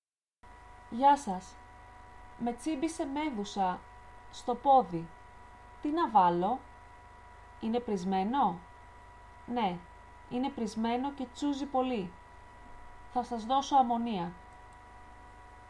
Dialog F: